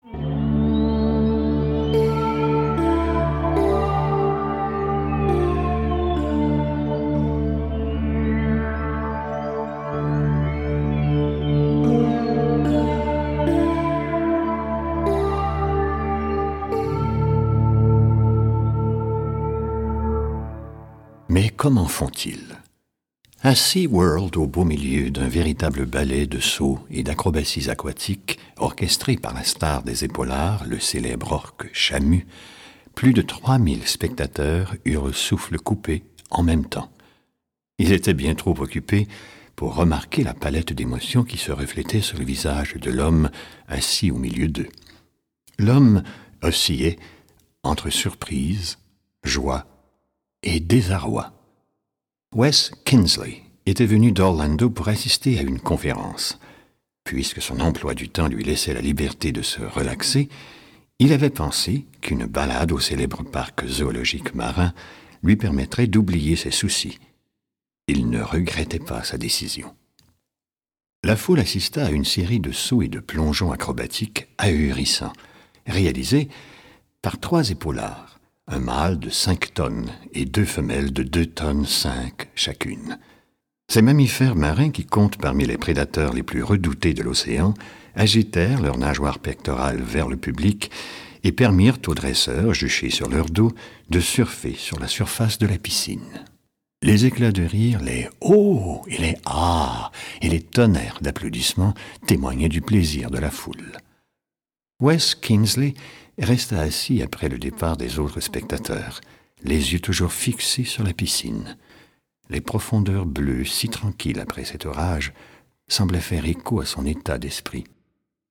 Click for an excerpt - Soyez fier de vous de Ken Blanchard